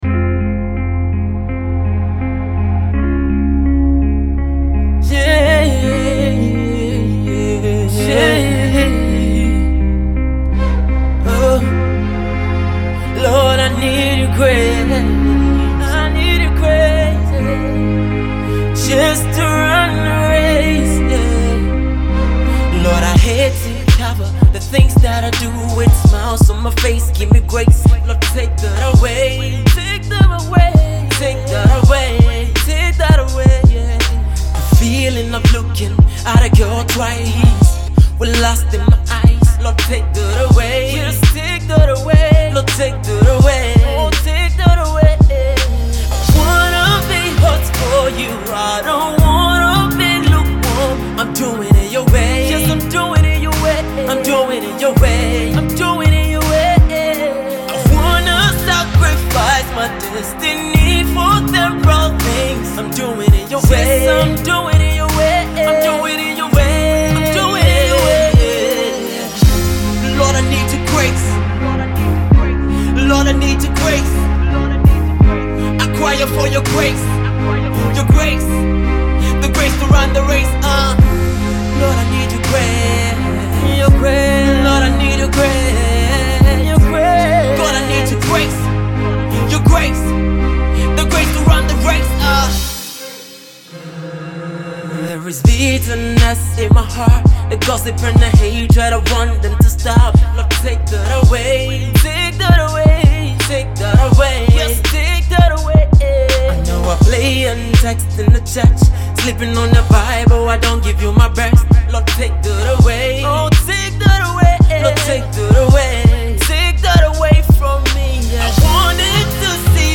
The Ghanian Gospel singer